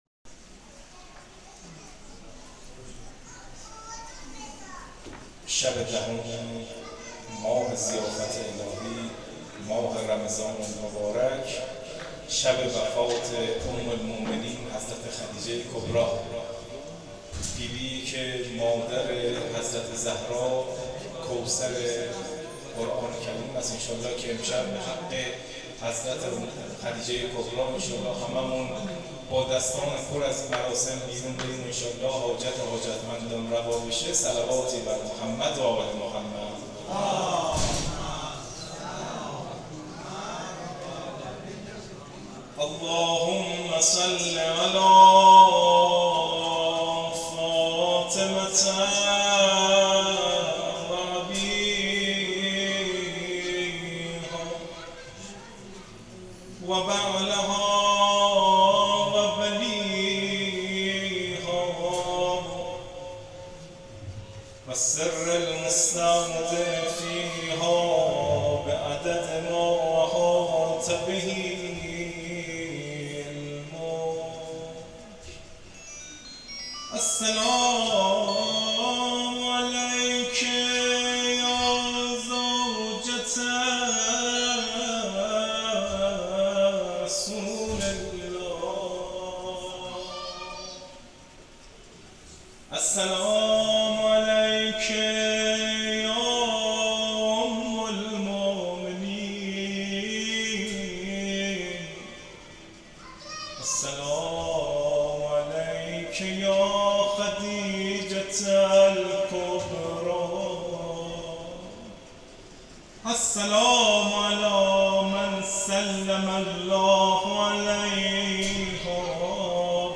مراسم وفات حضرت خدیجه سلام الله علیها